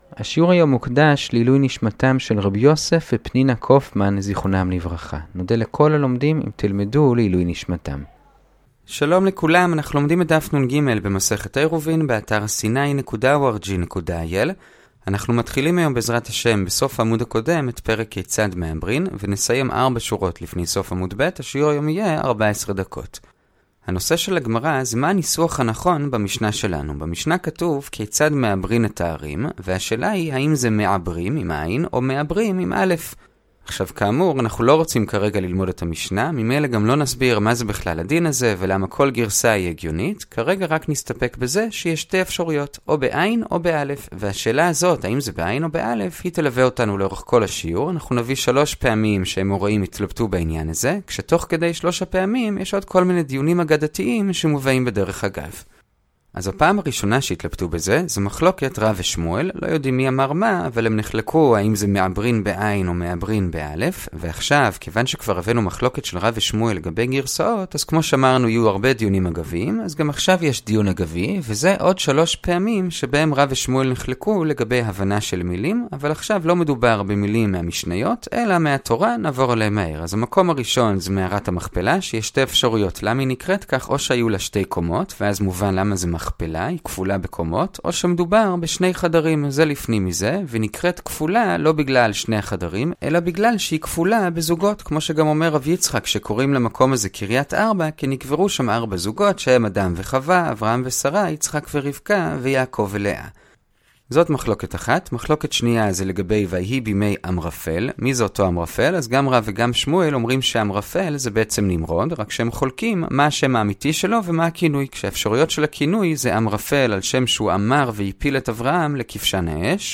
הדף היומי - עירובין דף נג - הדף היומי ב15 דקות - שיעורי דף יומי קצרים בגמרא